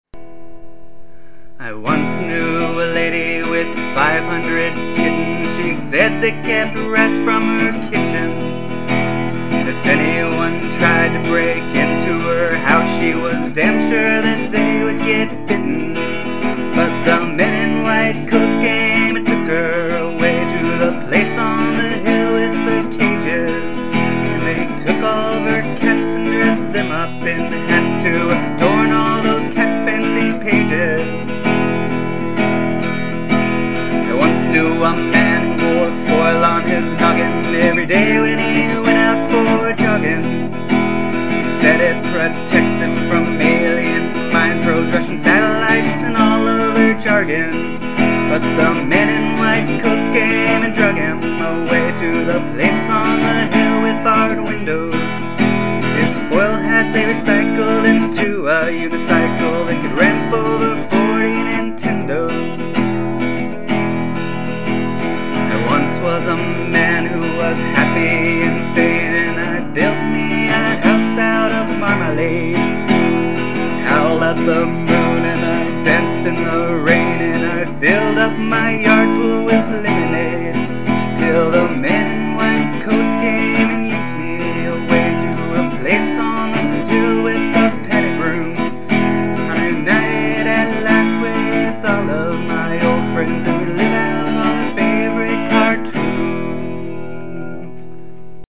Funny Songs:
"Happy And Sane". Or maybe... "The Moose Song" in which the tracks are out of sync.